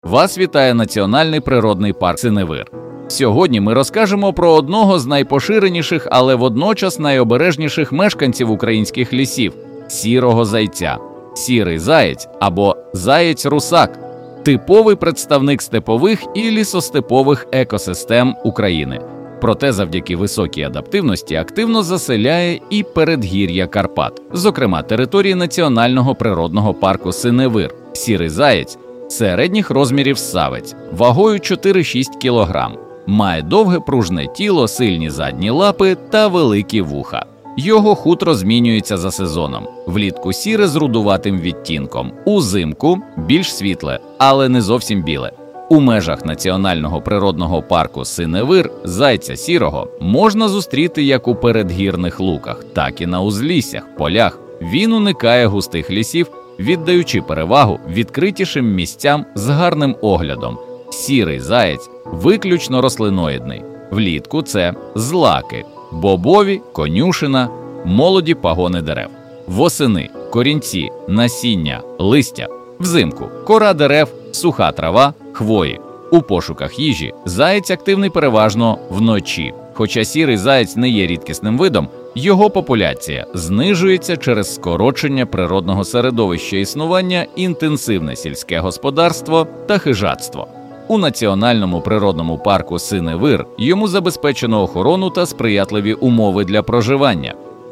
Аудіогід